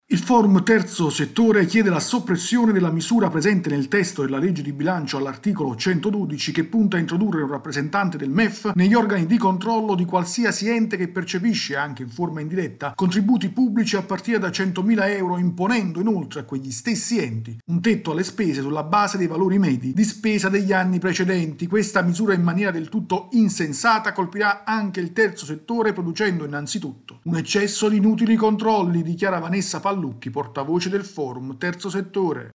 Il Forum Terzo settore chiede la soppressione dell’articolo 112 che introduce rappresentanti del Mef negli organi di controllo degli enti. Il servizio